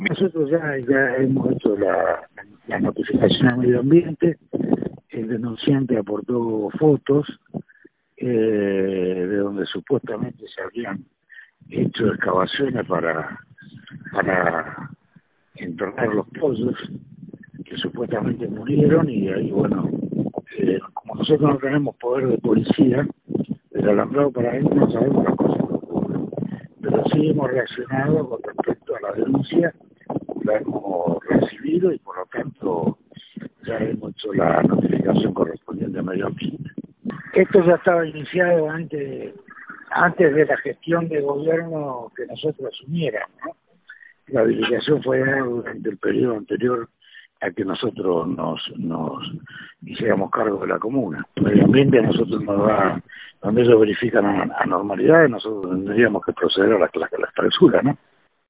En diálogo con Versión Rosario, Antonelli aseguró que el caso ya está en manos de las autoridades pertinentes: “Ya hemos hecho las notificaciones a Medio Ambiente. El denunciante aportó fotos donde supuestamente se habían hecho excavaciones para enterrar pollos que murieron”, comentó.
Nota-a-Omar-Antonelli-pte-comunal-de-Soldini.mp3